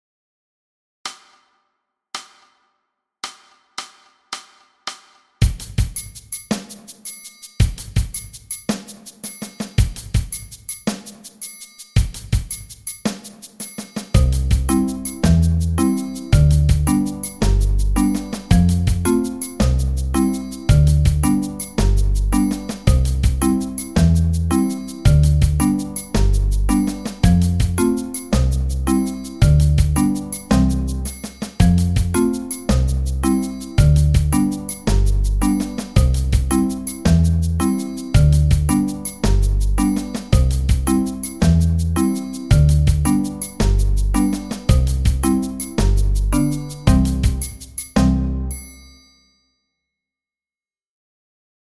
Danses de l’ours d’or – accompagnement à 110 bpm